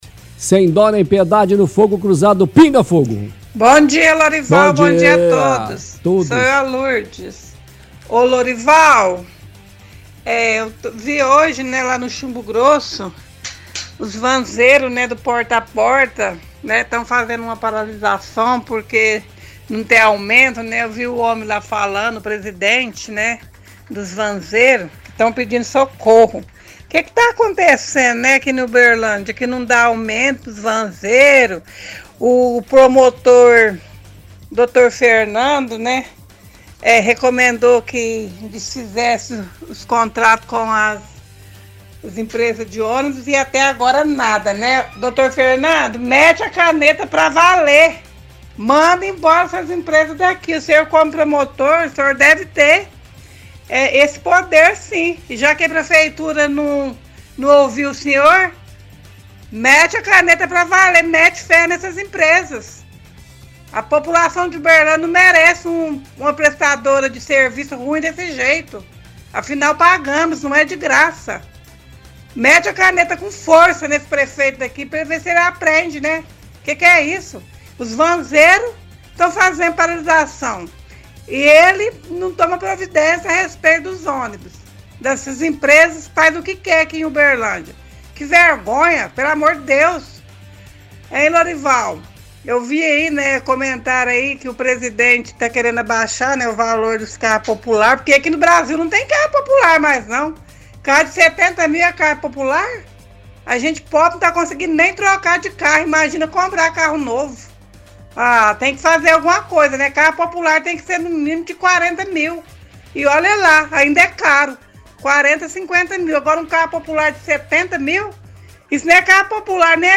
– Ouvinte reclama que o município não dá aumento para os vanzeiros após ter visto reportagem no Chumbo Grosso.